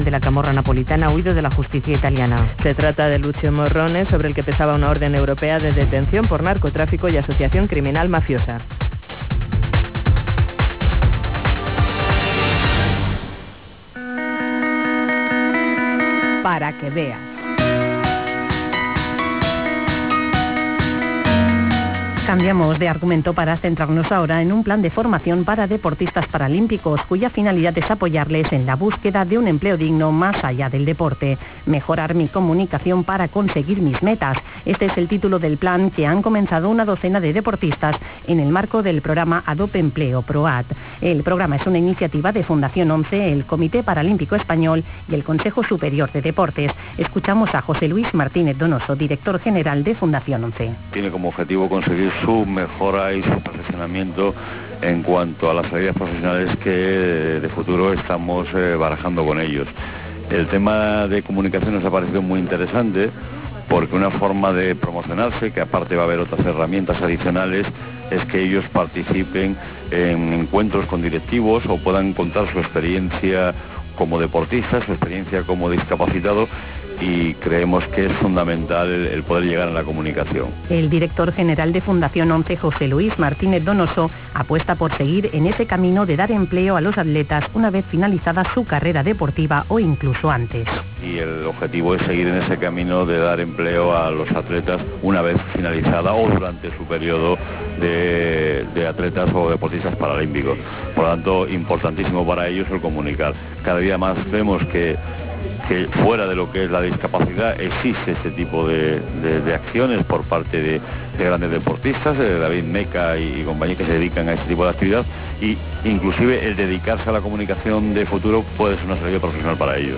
Fundación ONCE, el Comité Paralímpico Español y el CSD pusieron en marcha un plan de formación para deportistas paralímpicos. Para facilitar la inserción laboral y el desarrollo profesional de los atletas una vez terminada su carrera deportiva. Declaraciones